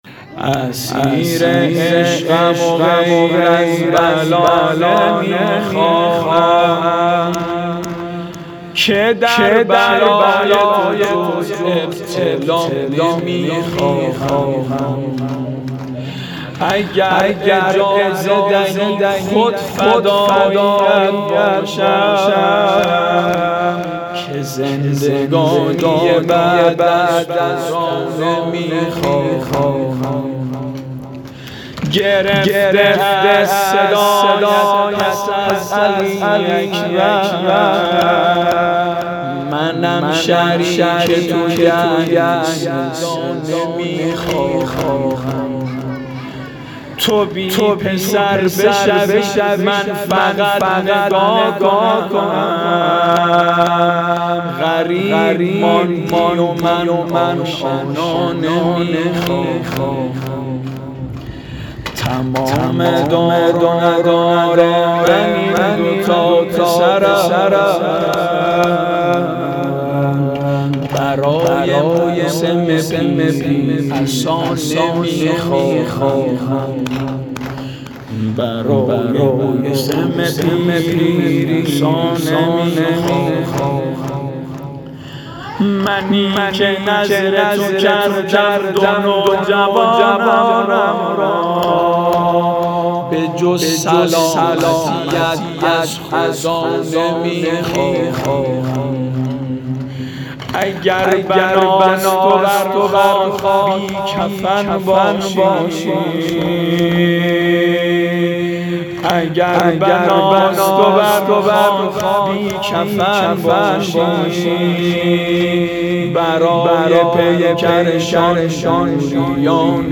واحد ، برای موسم عصا نمی خواهم ،شب چهارم ،فضای باز حسینیه معظم ریحانة الحسین سلام الله علیها